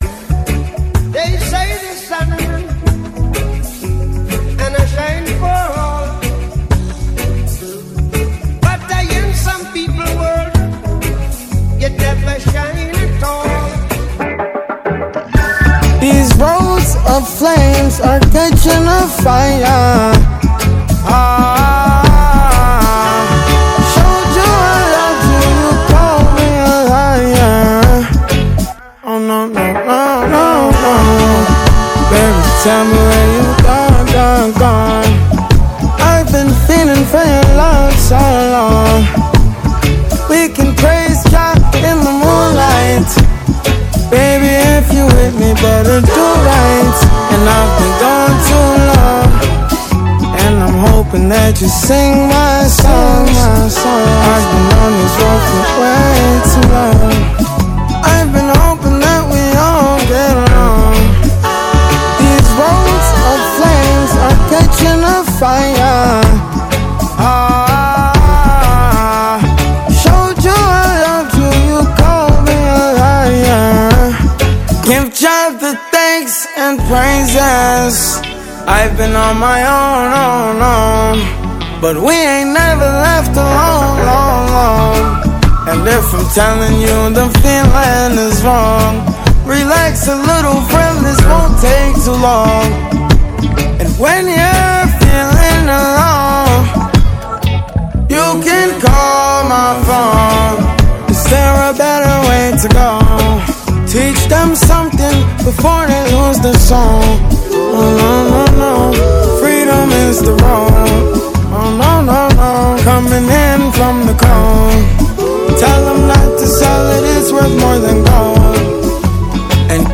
2024-03-05 21:10:20 Gênero: Reggae Views